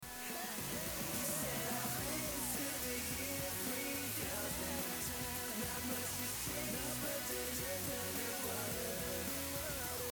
Using a Mordaunt-Short MS20 speaker, with music output a little above my normal listening level, recording the extracted and amplified 'error' signal of the original MJR6 and later listening to this alone the amplifier noise became clearly audible, together with some low level uncancelled music, but distortion is less easily identified.
Although amplified considerably this is still at a very low level.